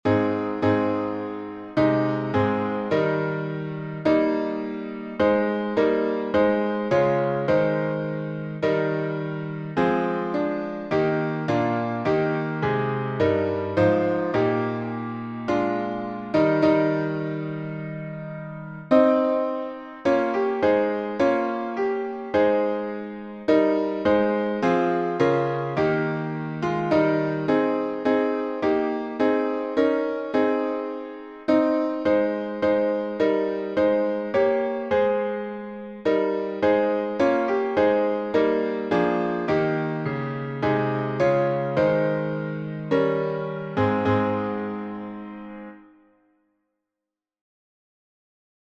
Verses 4, 5 translated by William T. Brooke (1848-1917) Tune: ADESTE FIDELES from John F. Wade's Cantus Diversei, 1751 Key signature: A flat major (4 flats) Public Domain Organ Performance at Hymns Without Words 1.